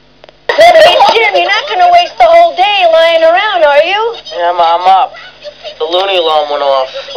Here are some wav sounds taken from the film